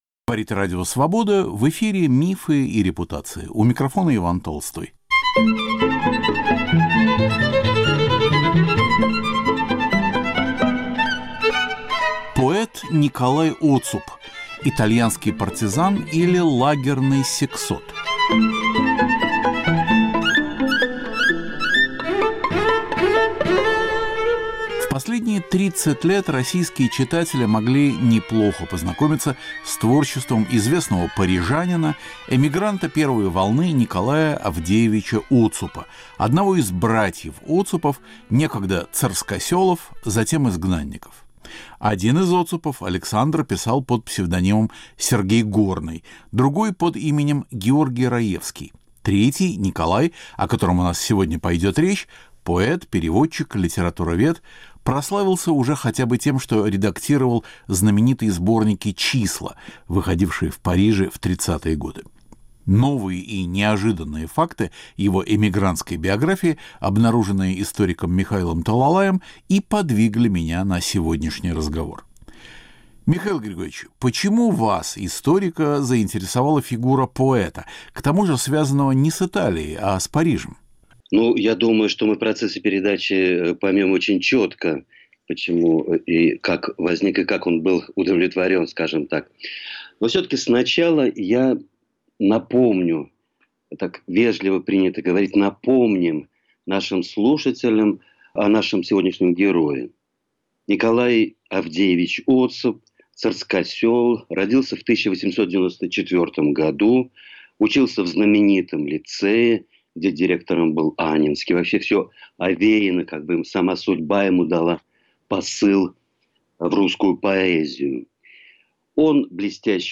Рассказывает историк